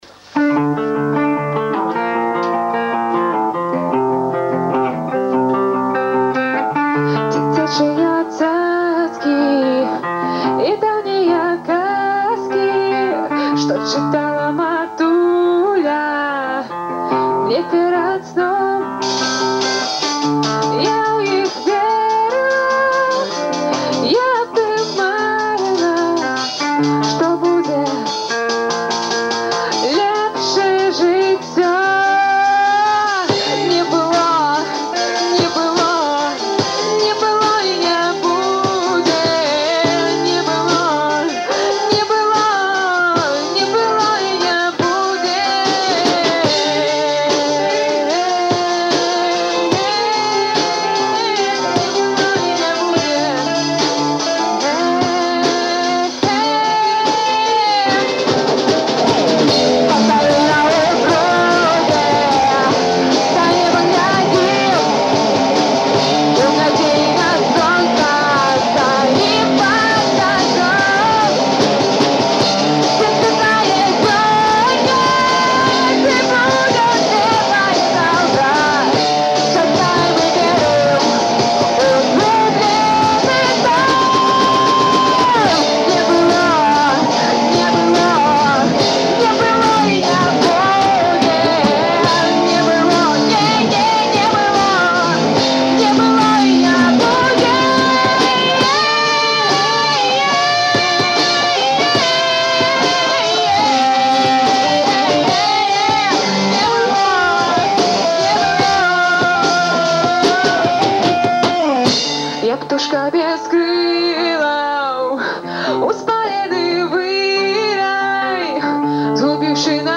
гитара, вокал
бас-гитара
барабаны, вокал